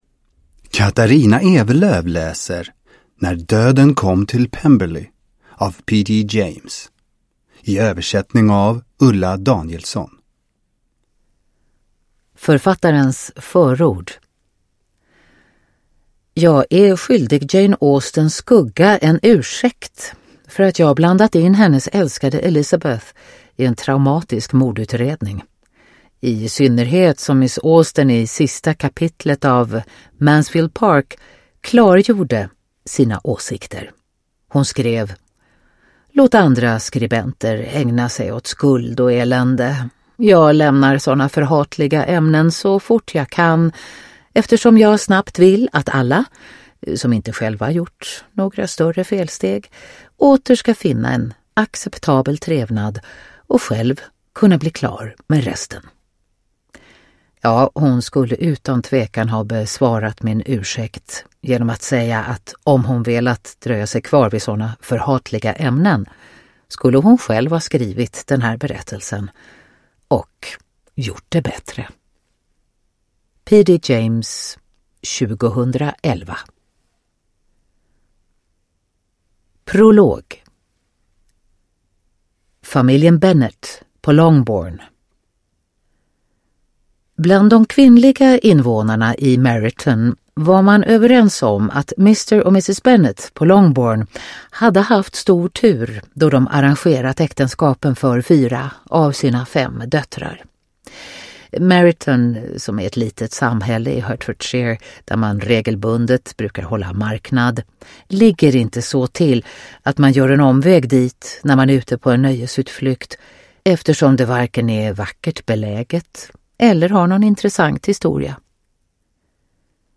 Uppläsare: Katarina Ewerlöf